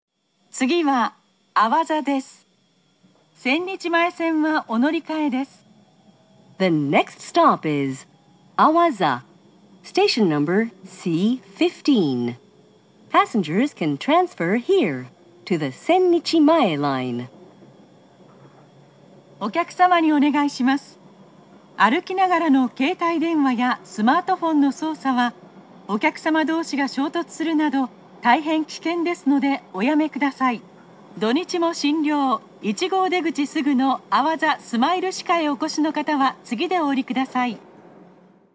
中央線車内放送2019